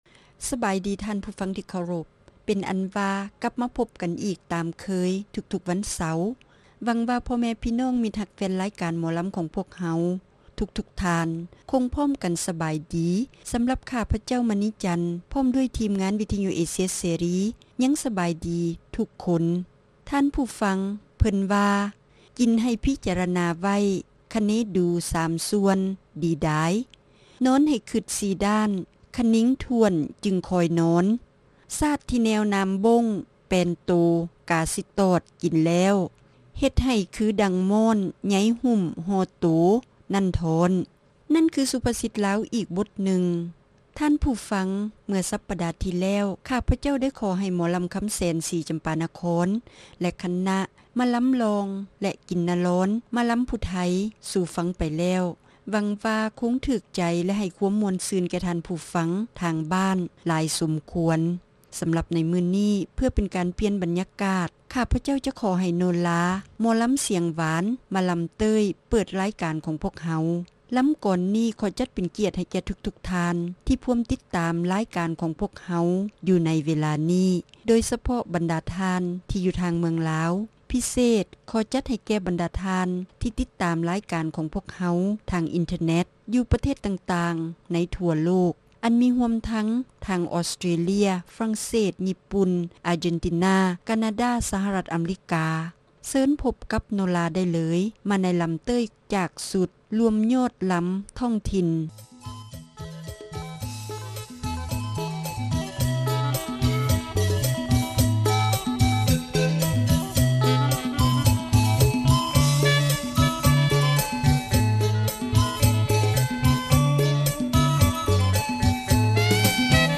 ຣາຍການໜໍລຳ ປະຈຳສັປະດາ ວັນທີ 5 ເດືອນ ຕຸລາ ປີ 2007